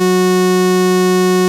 OSCAR 13 F#4.wav